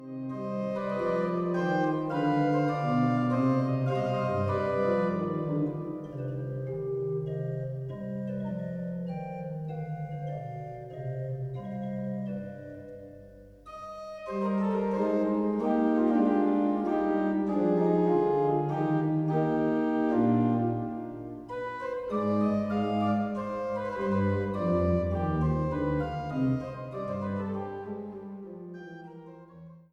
Eule-Orgel im Dom zu Zeitz